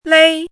chinese-voice - 汉字语音库
lei1.mp3